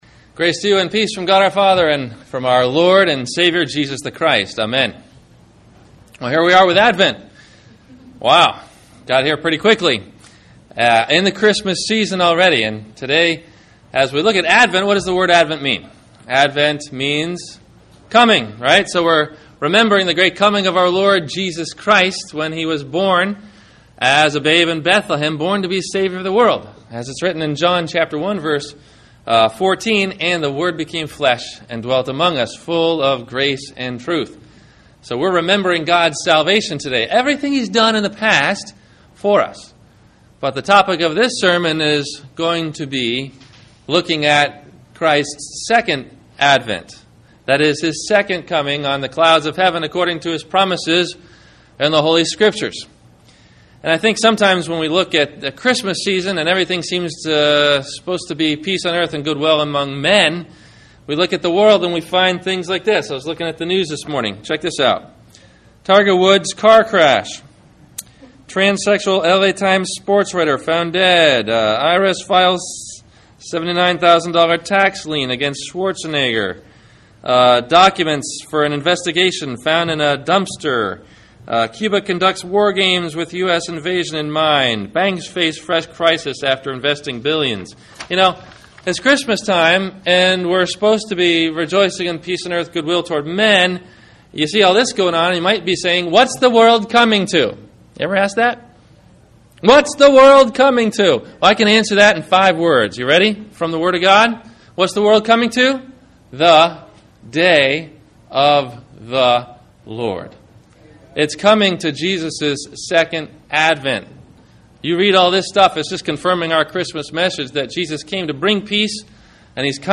The Day of the Lord – Sermon – November 29 2009